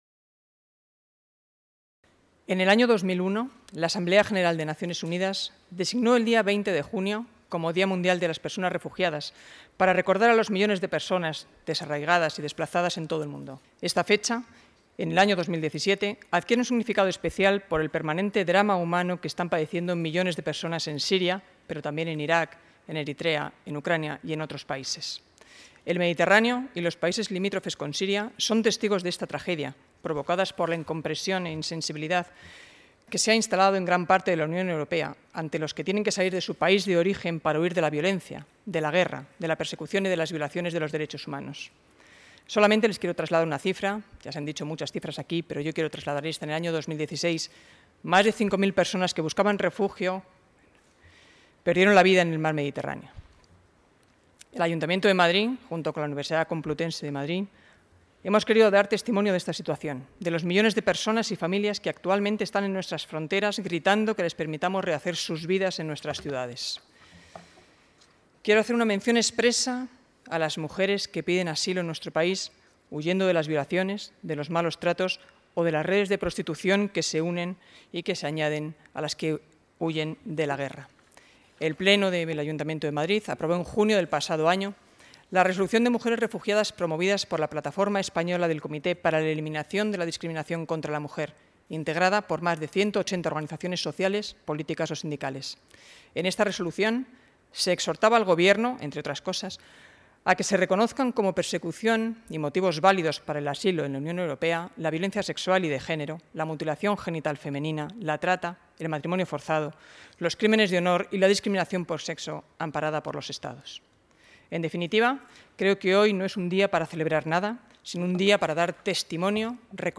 Nueva ventana:Palabras de Marta Higueras durante la entrega de premios